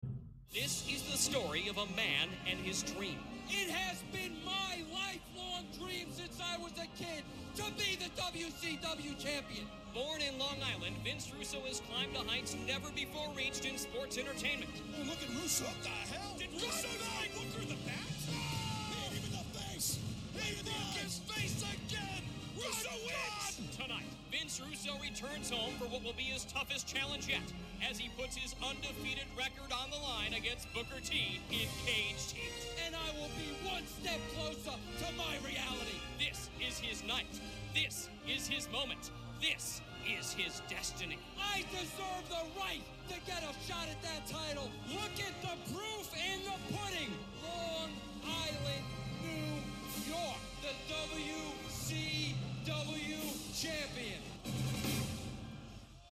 a voice over for the ages by Jeremy Borash.